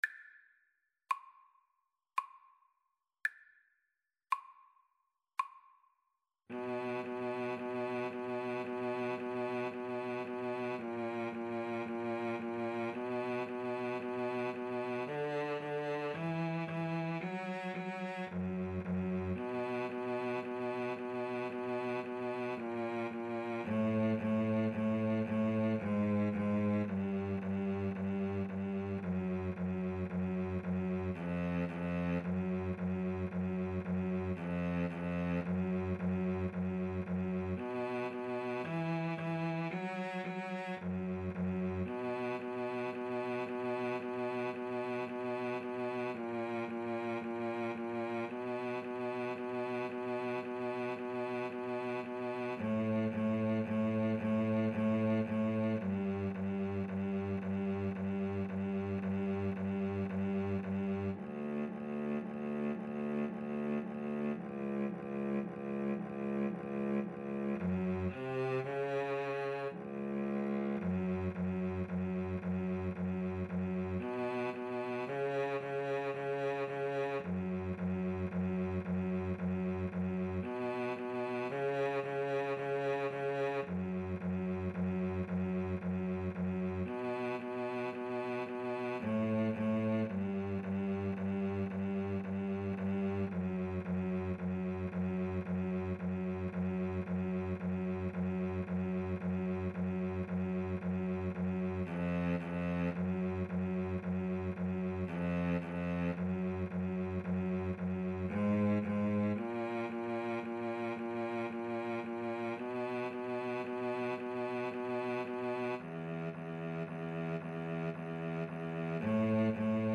= 56 Largo
Classical (View more Classical Cello Duet Music)